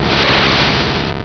Cri de Kadabra dans Pokémon Rubis et Saphir.